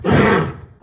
combat / creatures / horse
attack1.wav